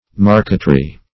marqueterie \mar"que*te*rie\, marquetry \mar"quet*ry\, n. [F.